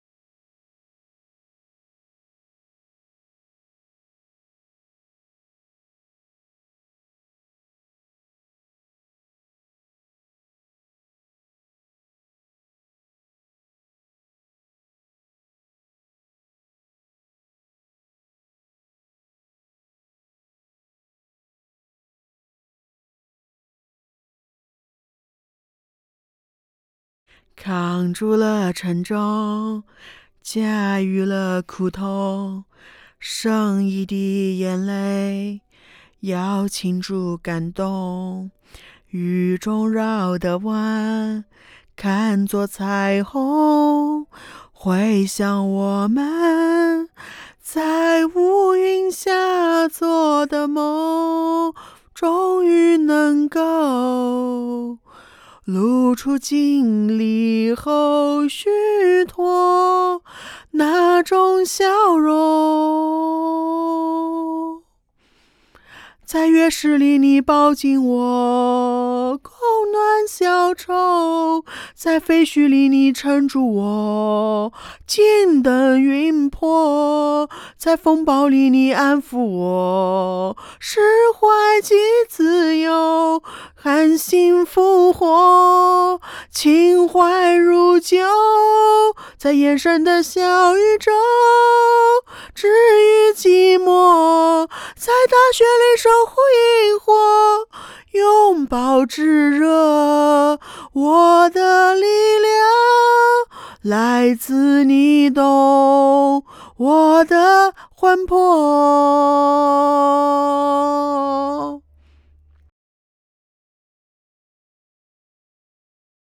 在录音棚